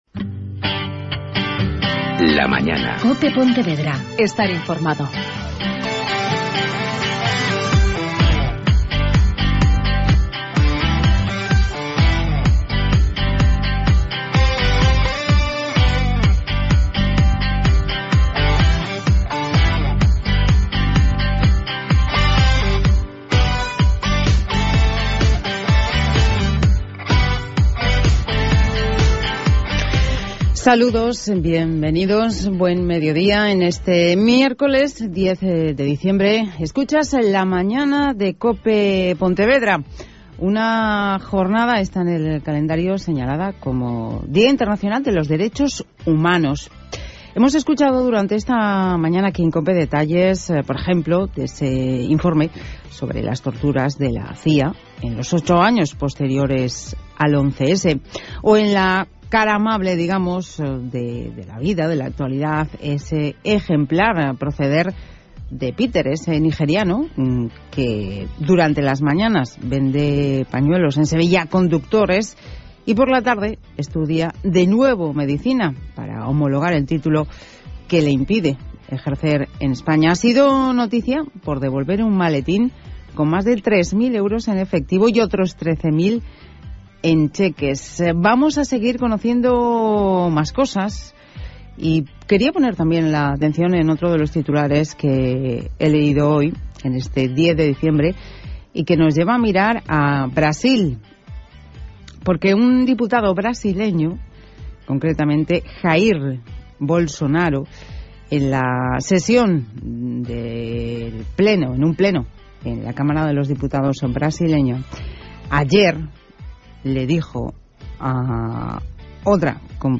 Radio: Podcast programa «La Mañana» COPE Pontevedra. Modular el mal carácter.10 Diciembre 2014
Mi intervención abarca desde el momento 20:40 hasta el final